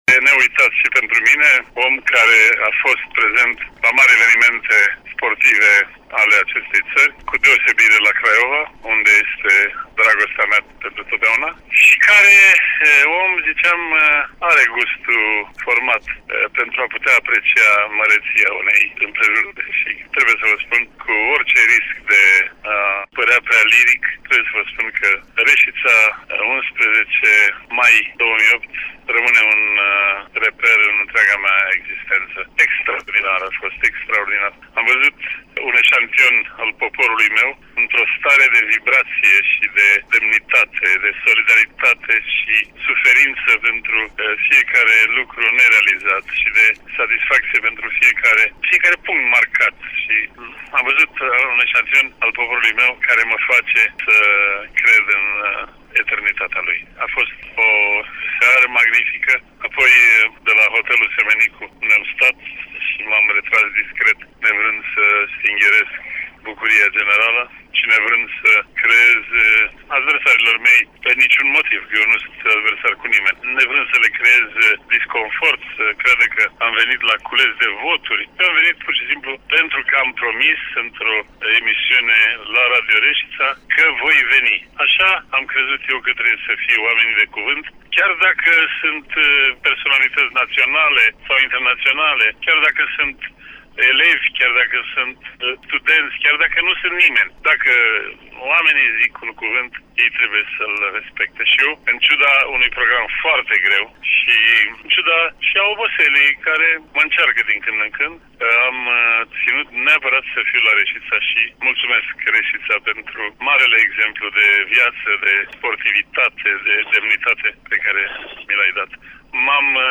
Timp de  cinci ani, Adrian Păuneascu a avut  o emisiune săptămânală la Radio România Reșița, care se difuza în  fiecare zi de  luni, cu o durată de 50 de minute.